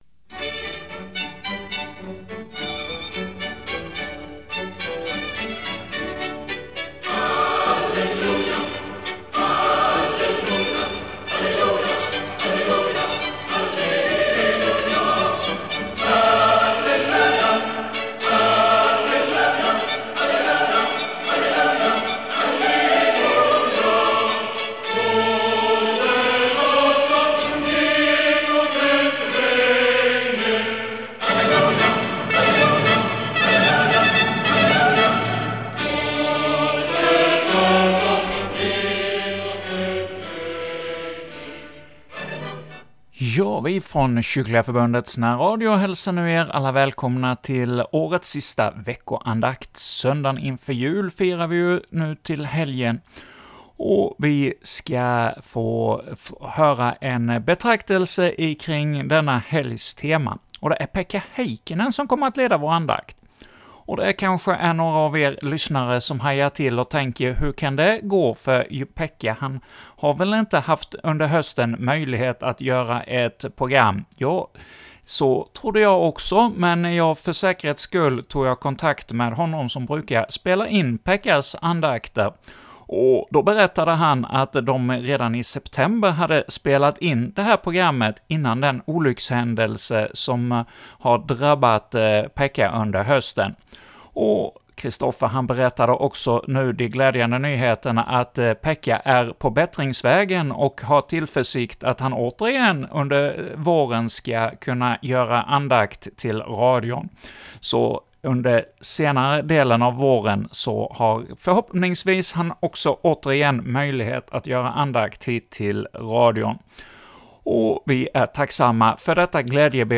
andakt inför söndagen efter jul